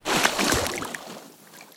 7f0caa168b Divergent / mods / Soundscape Overhaul / gamedata / sounds / material / human / step / t_water2.ogg 57 KiB (Stored with Git LFS) Raw History
t_water2.ogg